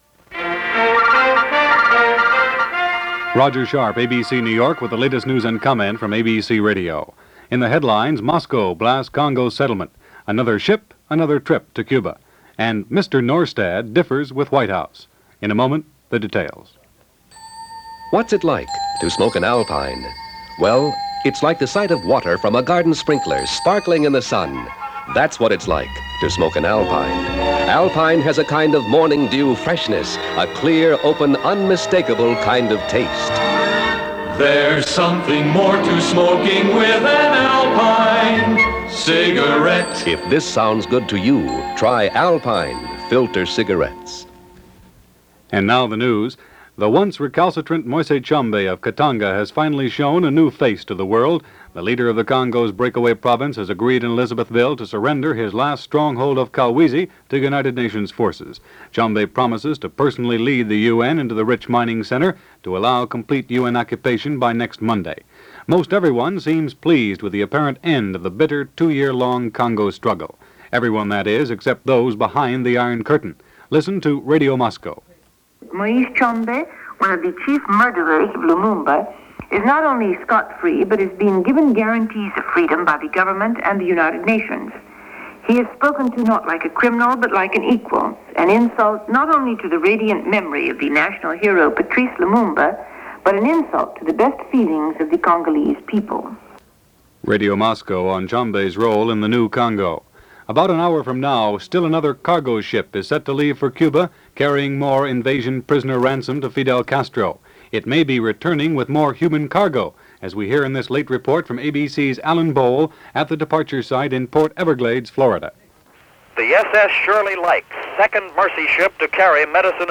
January 11, 1963 – ABC Radio News on The Hour – Gordon Skene Sound Collection –